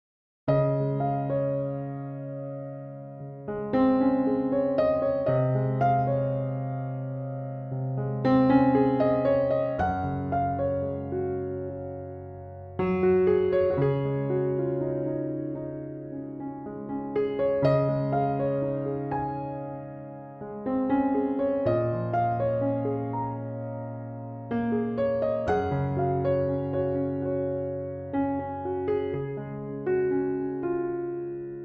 30-segundos-｜-Paisajes-｜-4K-｜-Naturaleza-｜-Musica-Relajante-｜Relajacion-｜-Relax.mp3